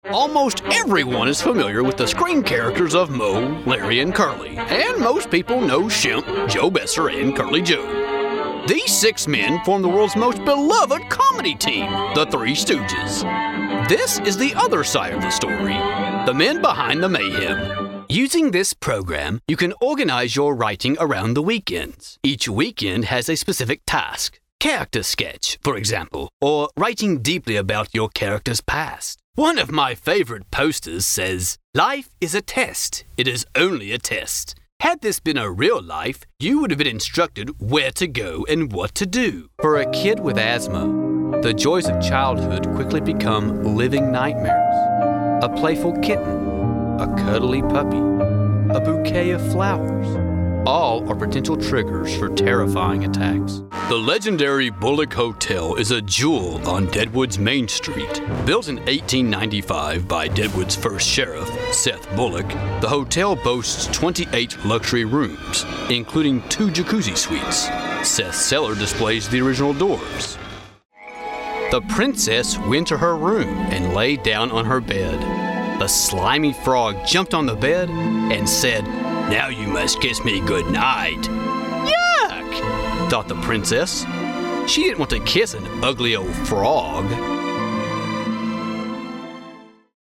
narration, amusing, funny
mid-atlantic
Sprechprobe: eLearning (Muttersprache):